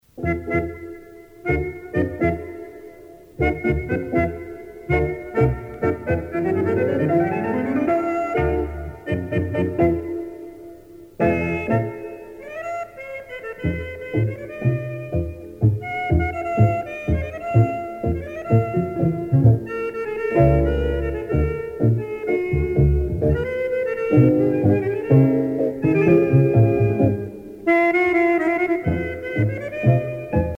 tango musette
Pièce musicale éditée